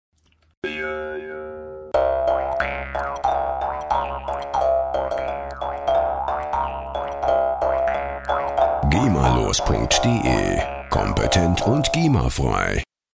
Jews Harp
Instrument: Mundtrommel
Tempo: 90 bpm
jews-harp.mp3